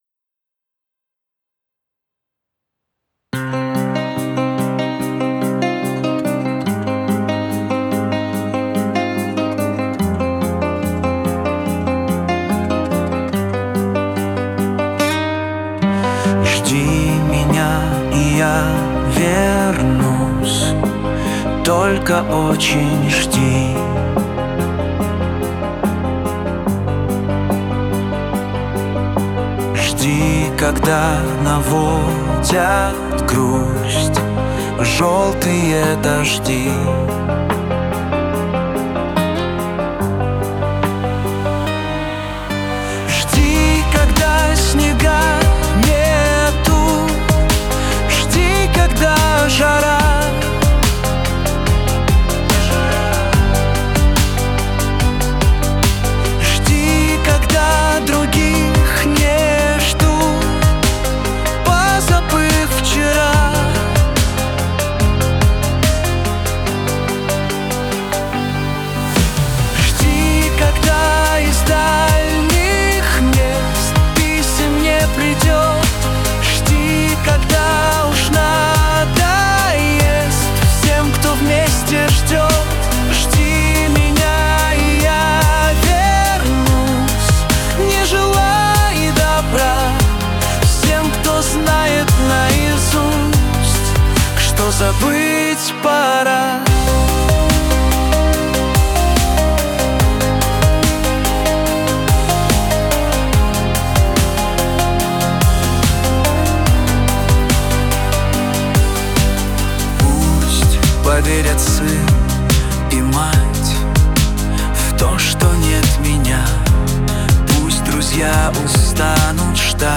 • Качество: Хорошее
• Жанр: Детские песни
военные песни